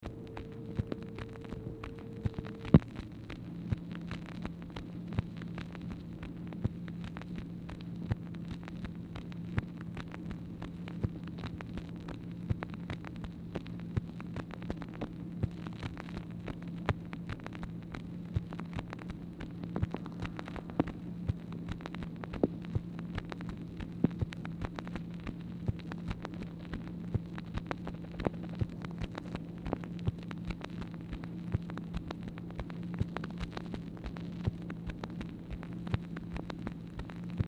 Telephone conversation # 2638, sound recording, MACHINE NOISE, 3/24/1964, time unknown | Discover LBJ
Telephone conversation
Format Dictation belt